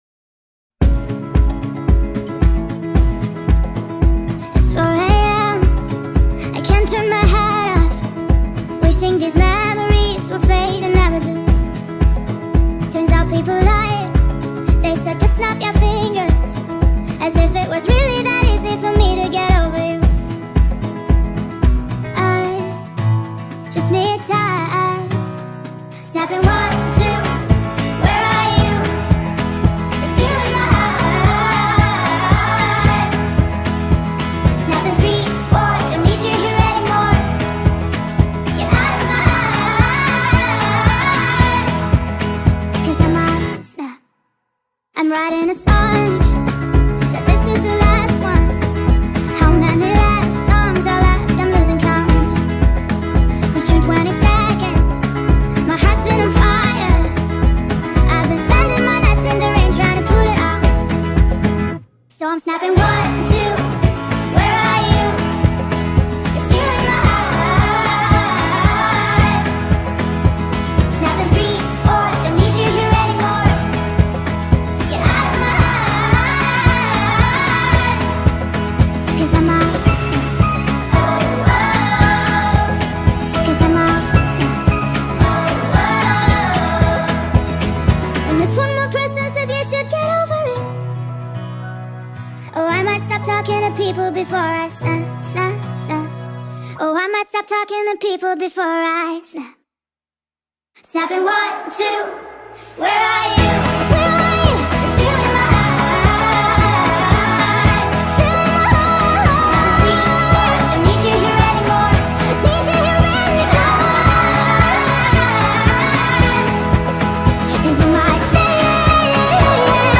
نسخه Sped Up و سریع شده در ریتمی تند
با وایبی عاطفی و احساسی
غمگین